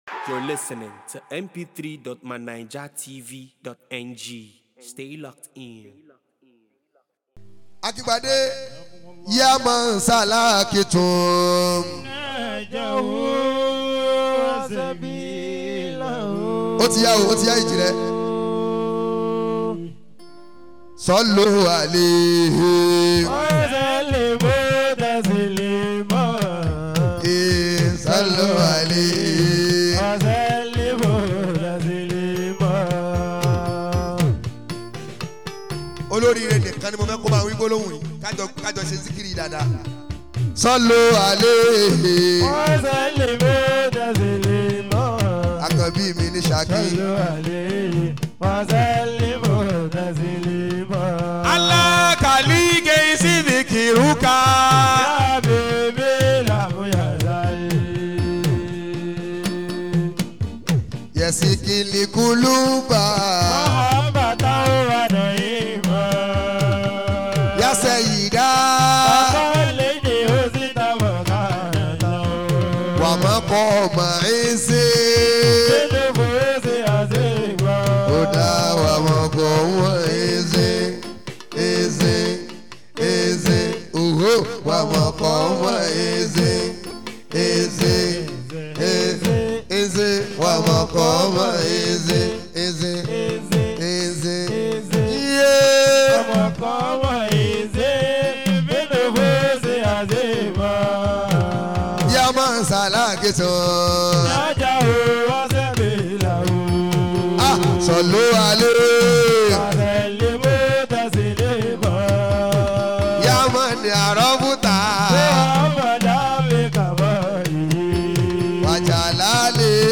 Annual Ta'zeemur Rosuul & Fund Raising for Asalatu Project.